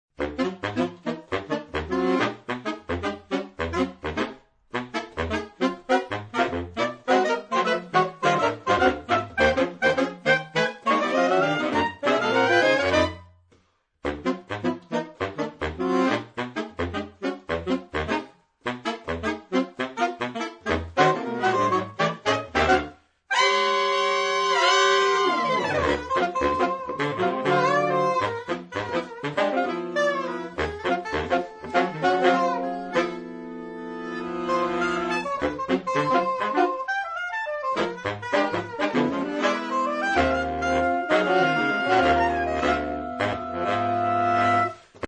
noty pro saxofon
4 Saxophone (SATBar)